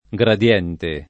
gradiente
gradiente [ g rad L$ nte ] s. m. (fis.)